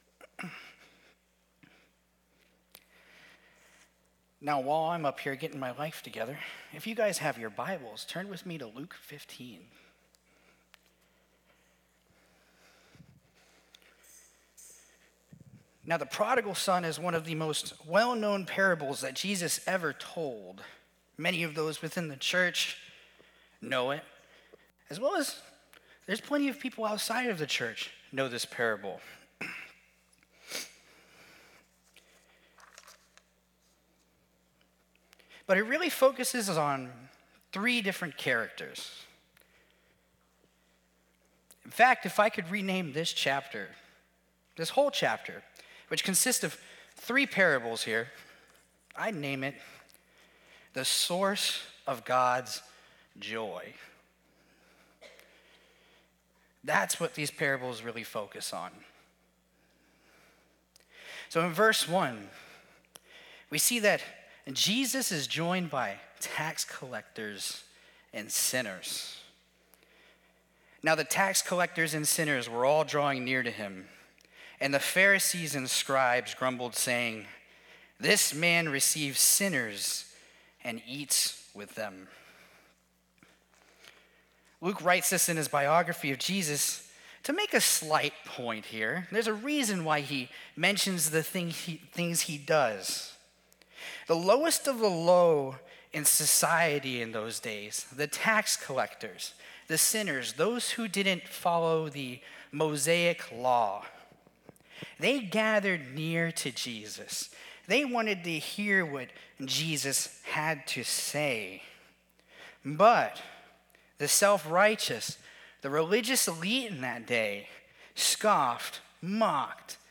Sermons | New Life Alliance Church